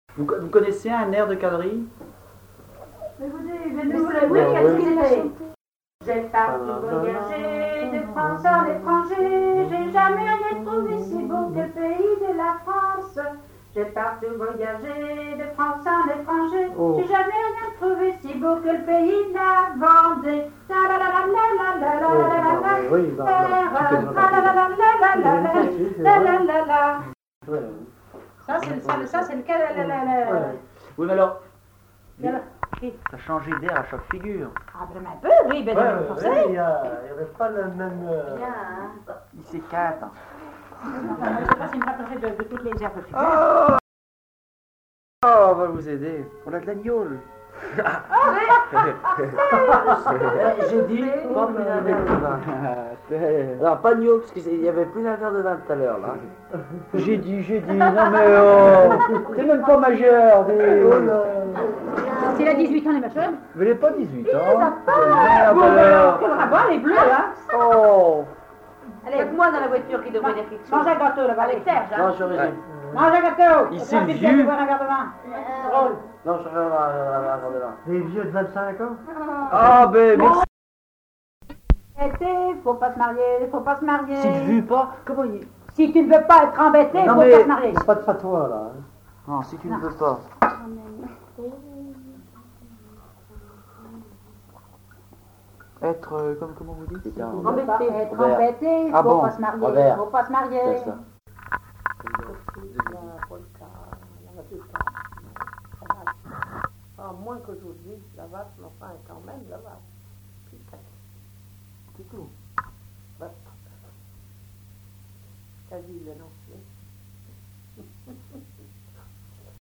Airs de quadrille
Chants brefs - A danser
danse : quadrille
Chansons traditionnelles et populaires